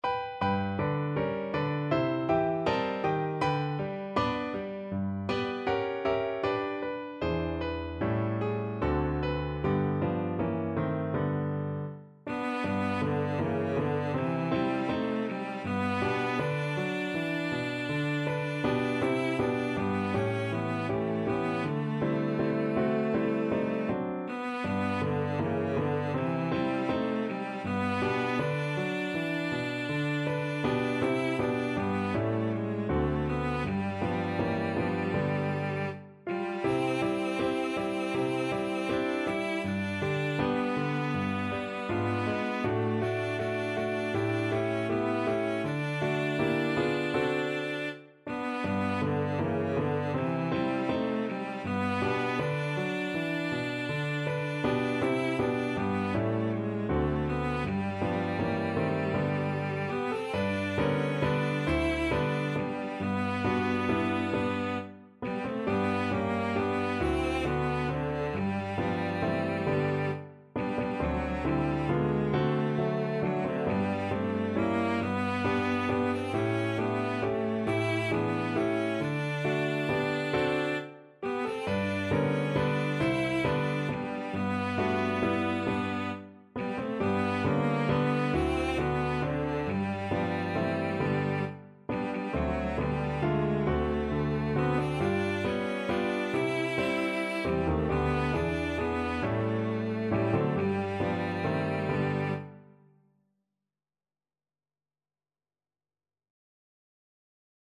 Free Sheet music for Cello
Cello
G major (Sounding Pitch) (View more G major Music for Cello )
Andante
4/4 (View more 4/4 Music)
Pop (View more Pop Cello Music)